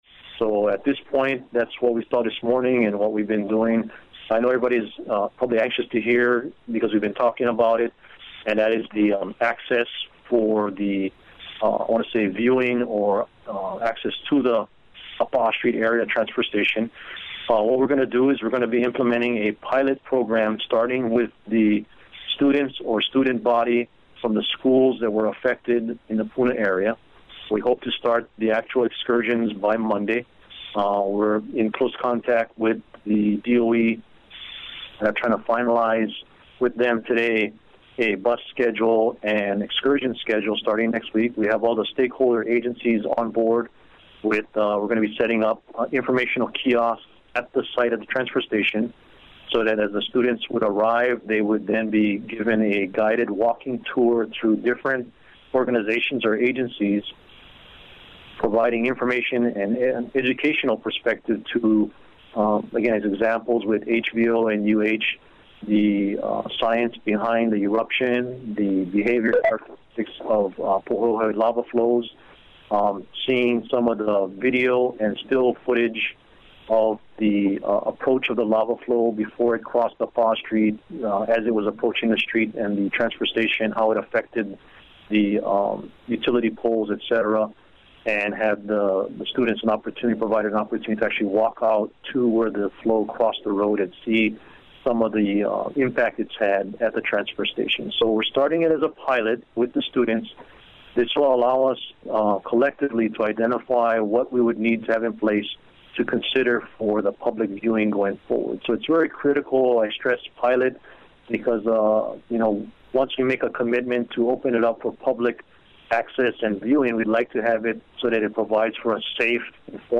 Here is what Civil Defense administrator Darryl Oliveira said about the planned public viewing area pilot project with Puna schools. The audio was taken from the Dec. 3 media conference call.